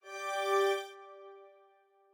strings7_1.ogg